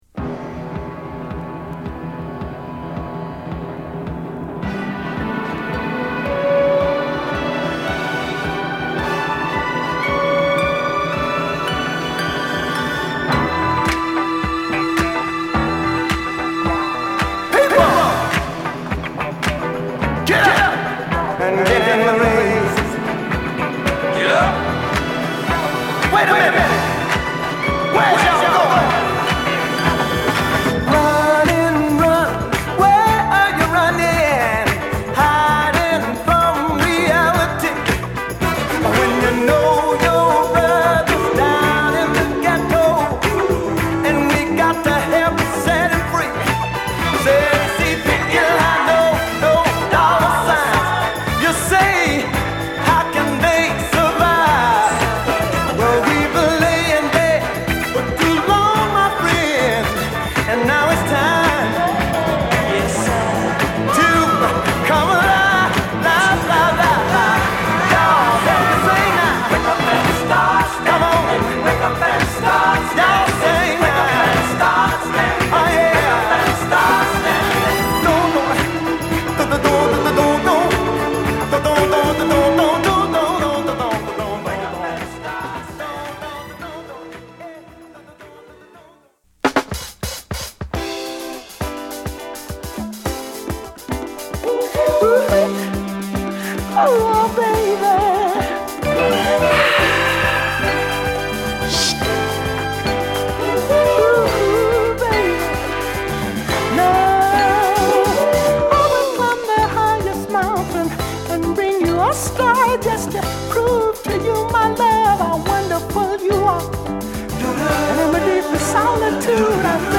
LA発のヴォーカルグループ